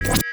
UIMvmt_PopUp System Appear.wav